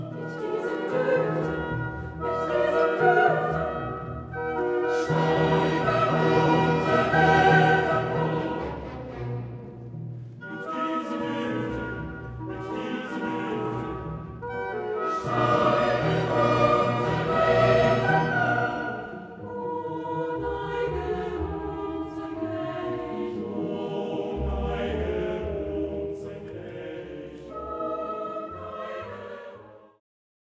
Arie (Chamital)